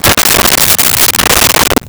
Dresser Drawer Open 01
Dresser Drawer Open 01.wav